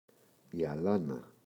αλάνα, η [aꞋlana]